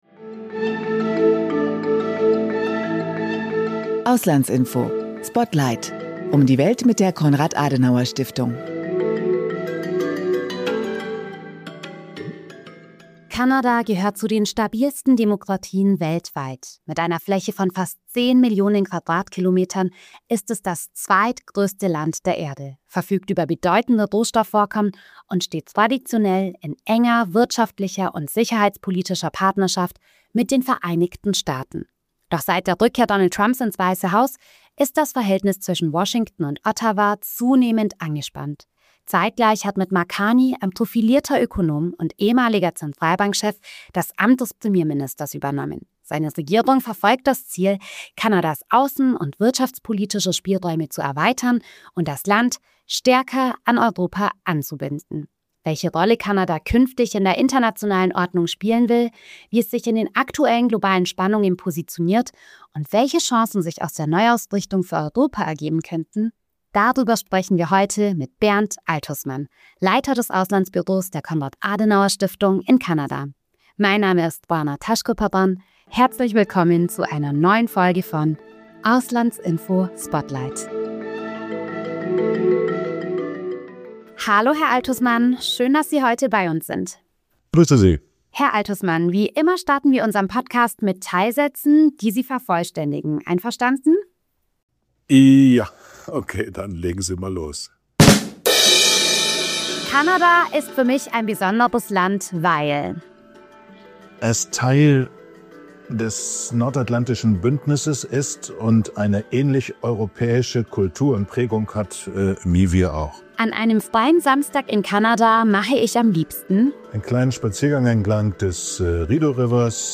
Ihre Expertinnen und Experten erklären internationale Politik und geben im Gespräch Hintergrundinformationen zu aktuellen Entwicklungen und Ereignissen.